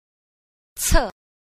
10. 冊 – cè – sách